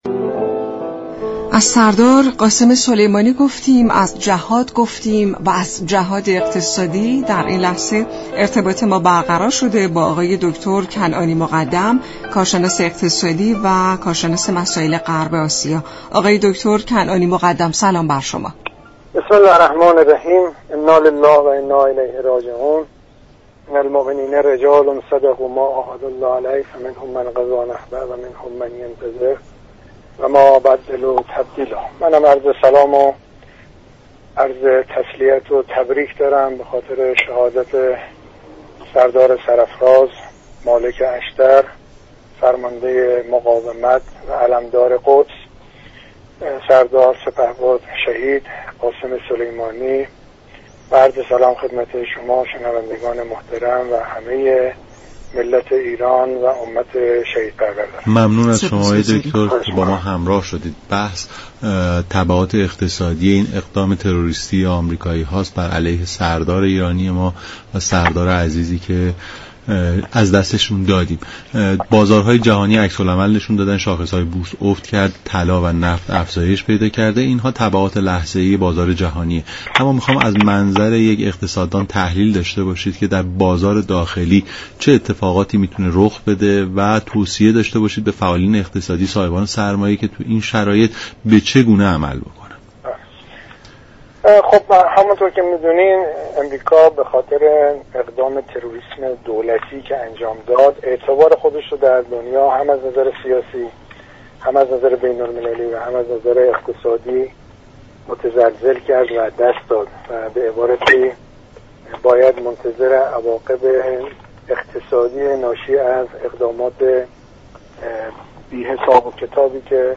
در گفت و گو با رادیو ایران